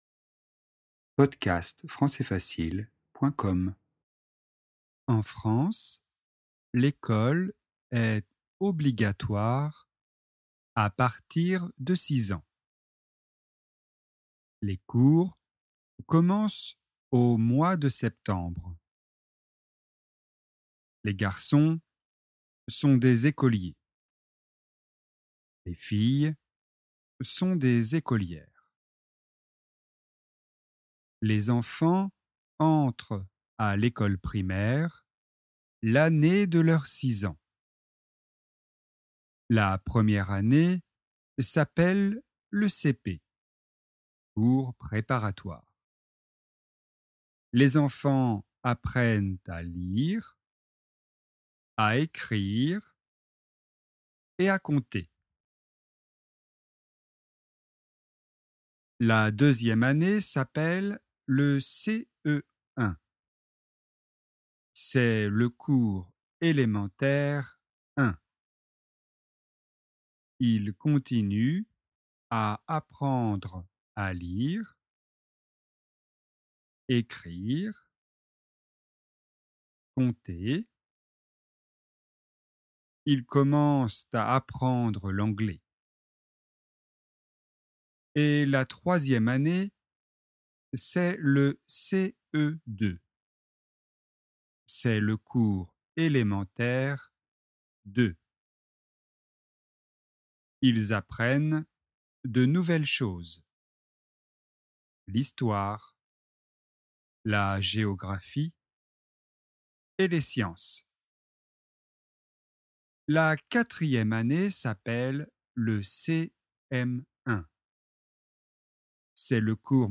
L'école primaire (version lente)
ecole-primaire-version-lente.mp3